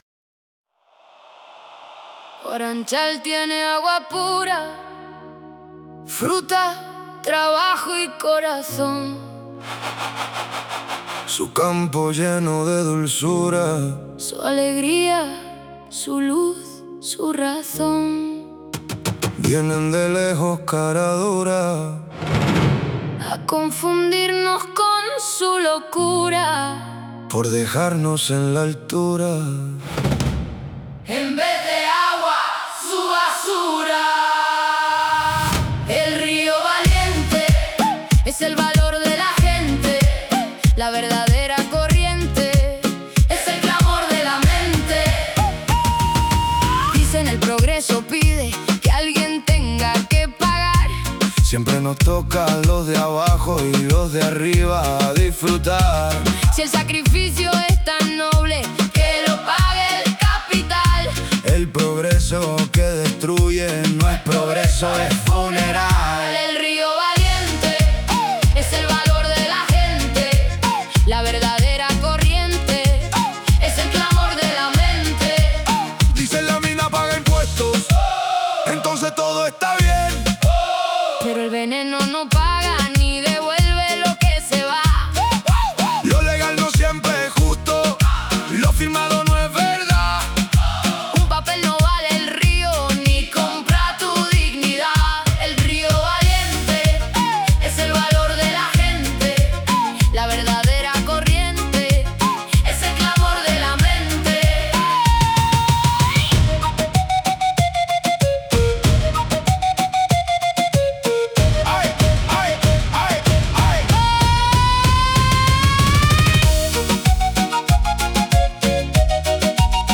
Regatón